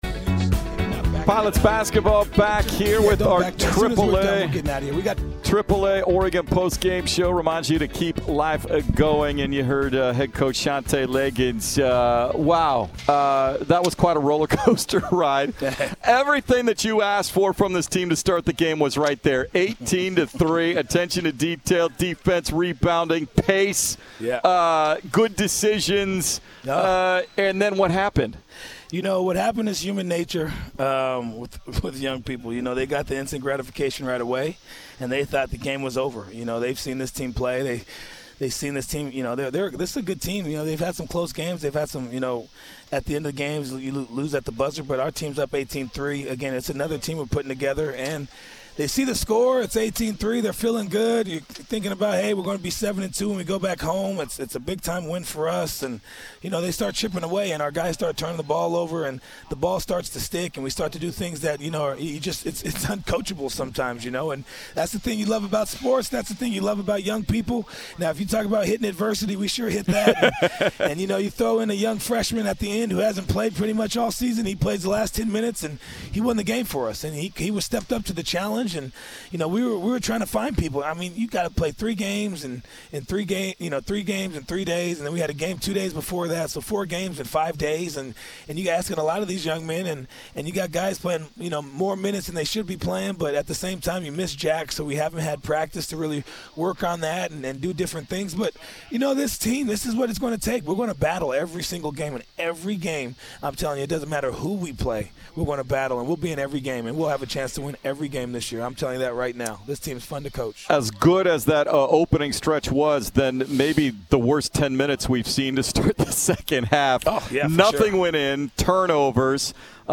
Men's Basketball Post-Game Radio Interviews at Incarnate Word